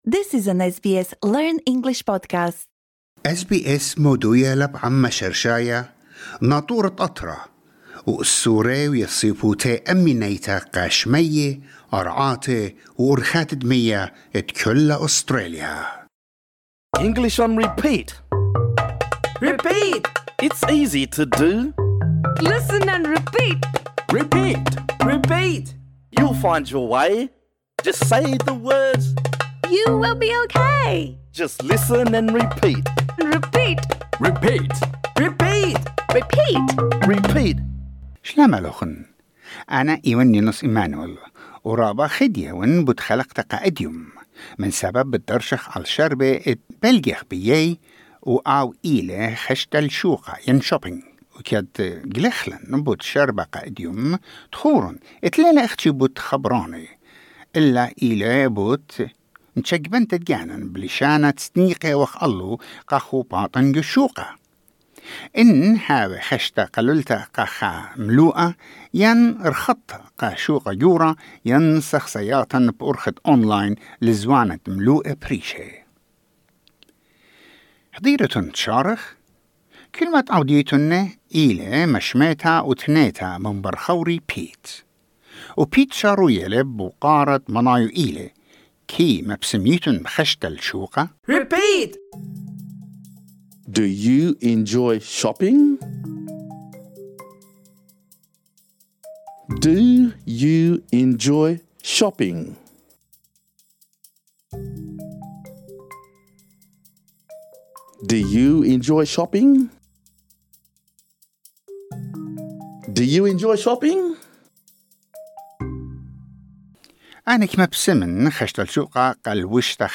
This lesson is designed for easy-level learners.